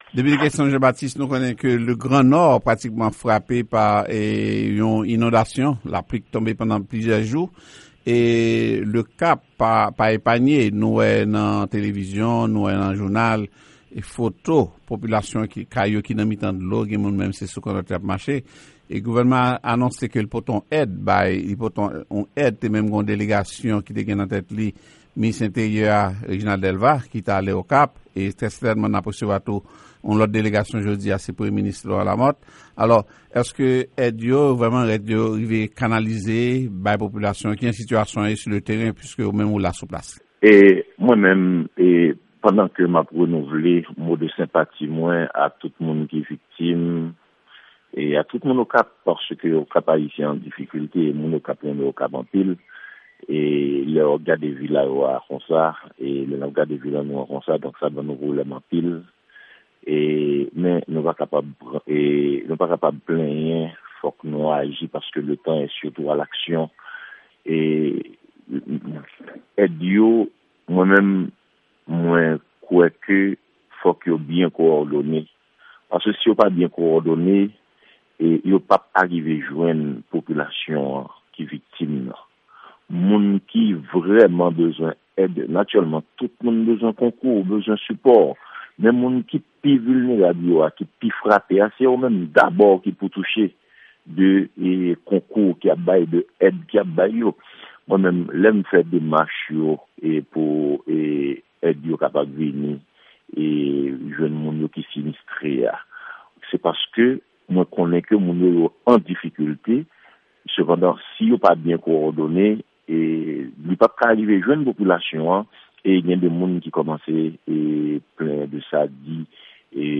Entèviou Depite Kenston Jean Baptiste sou inondasyon nan vil Okap jedi 6 novanm